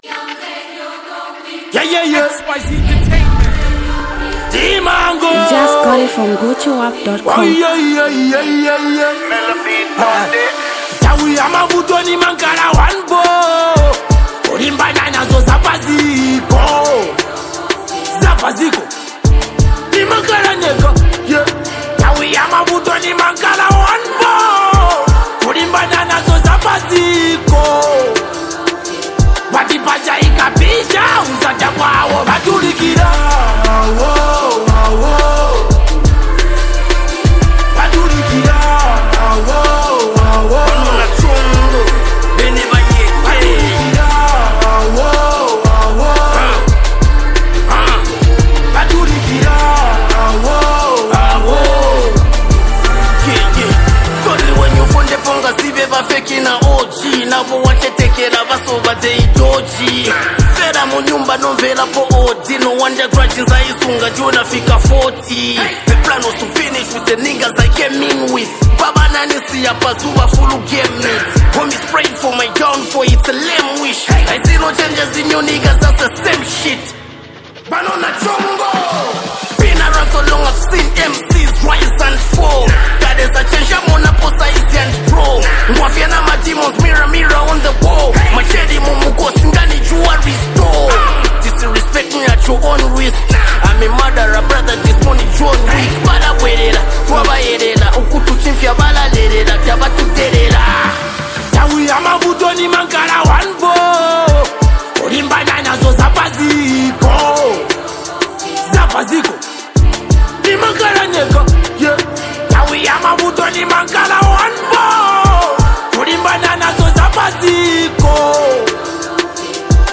Zambian Mp3 Music
rapper